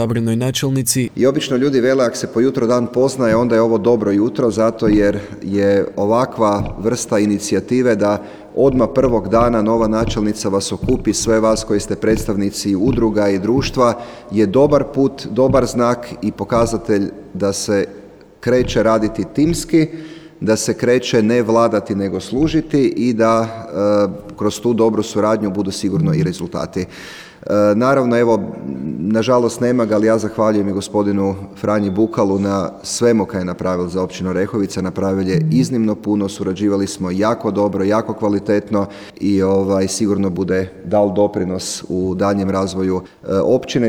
Novoj načelnici, zamjeniku načelnice iz redova pripadnika romske nacionalne manjine Marku Balogu i svim mještanima čestitku je povodom Dana općine uputio župan Matija Posavec te istaknuo: